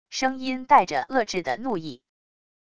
声音带着遏制的怒意wav音频